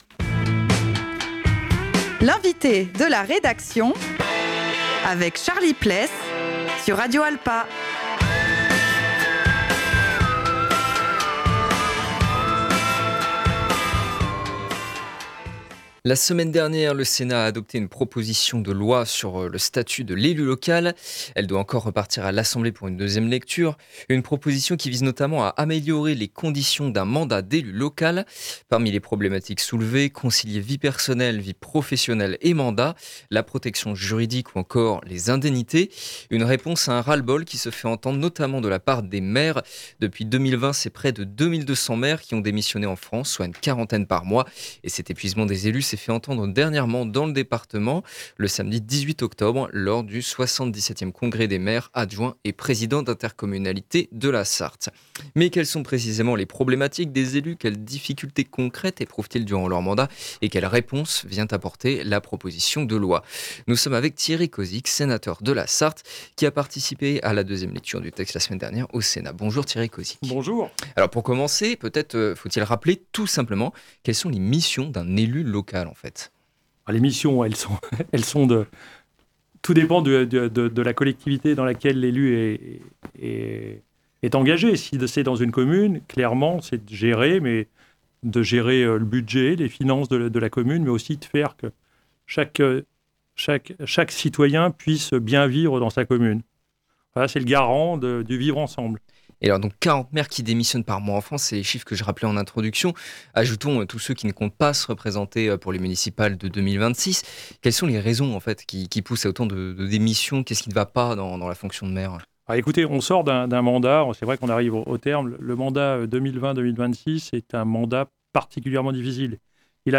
Nous sommes avec Thierry Cozic, sénateur de la Sarthe, qui a participé à la deuxième lecture du texte la semaine dernière au Sénat.